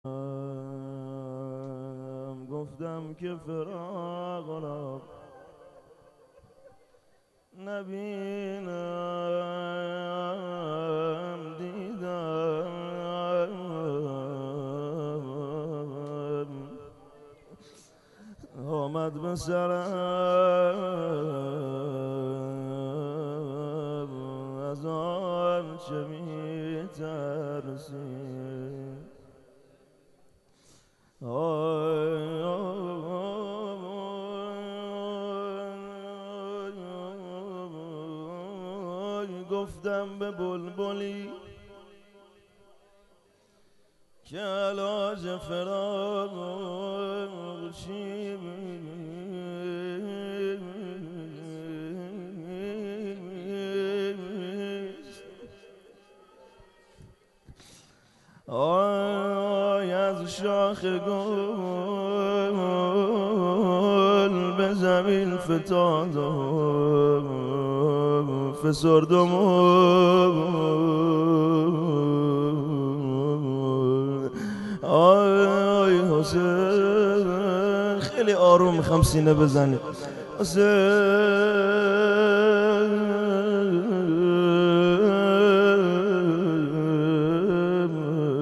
روضه